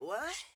Vox Uzi Wut.wav